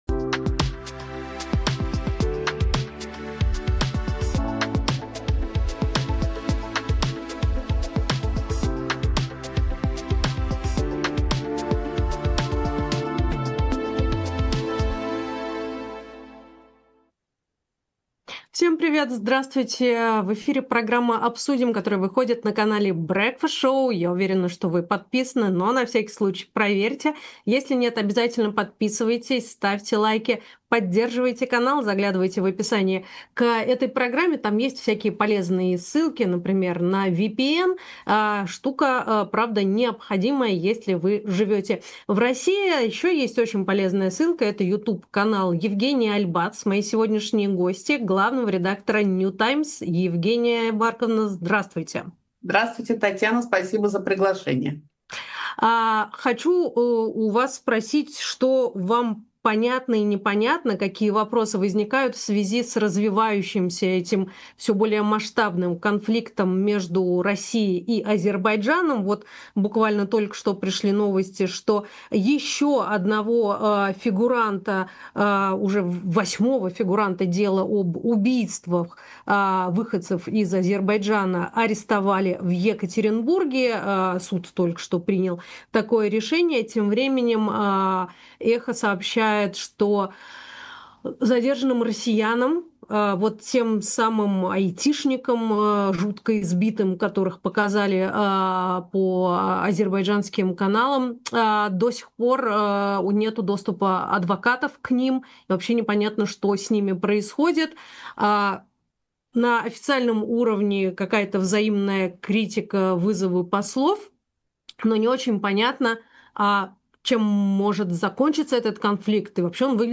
Эфир ведёт Татьяна Фельгенгауэр